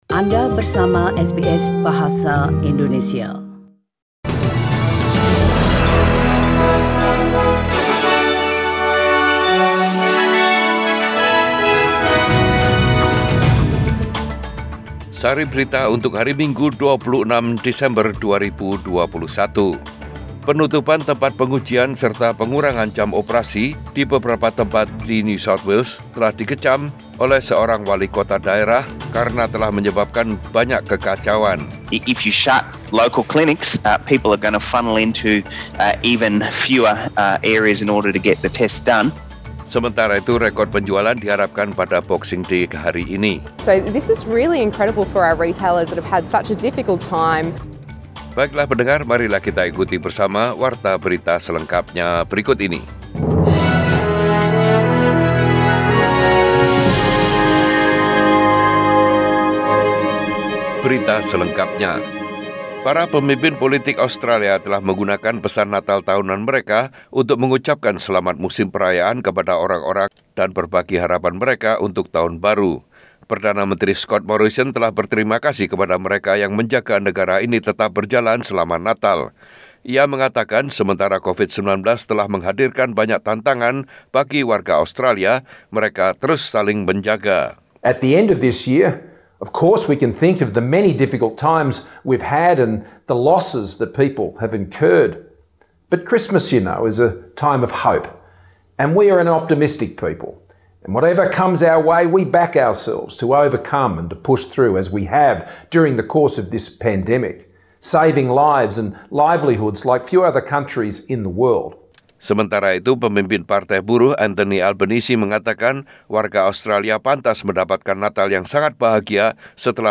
SBS Radio News in Bahasa Indonesia - 26 December 2021
Warta Berita Radio SBS Program Bahasa Indonesia.